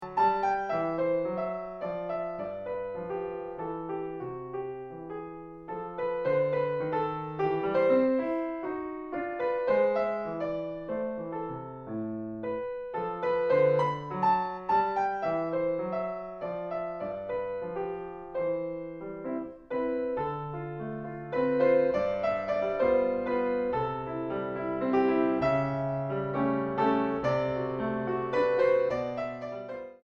En do mayor 1.09